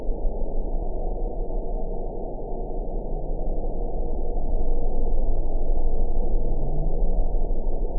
event 914838 date 11/03/22 time 00:31:37 GMT (2 years, 7 months ago) score 7.01 location INACTIVE detected by nrw target species NRW annotations +NRW Spectrogram: Frequency (kHz) vs. Time (s) audio not available .wav